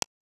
raton.wav